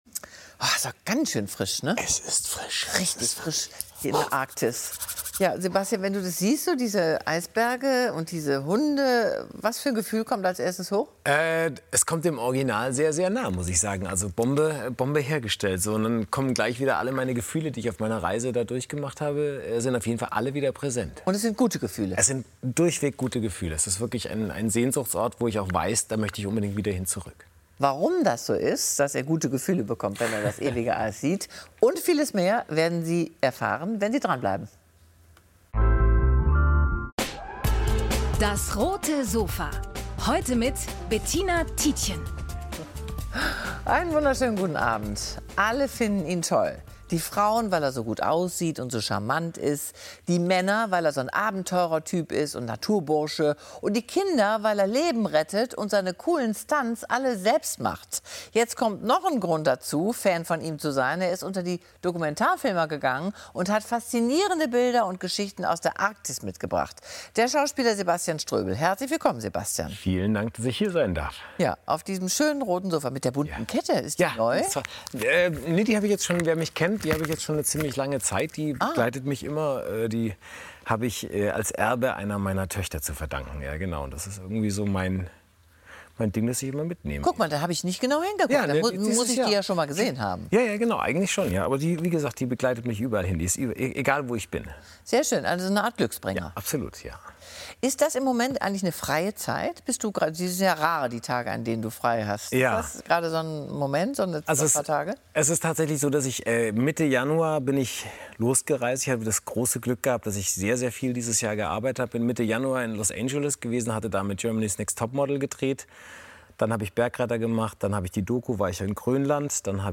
Sebastian Ströbel über Gletscher, Schneewüsten und die Schönheit der Natur ~ DAS! - täglich ein Interview Podcast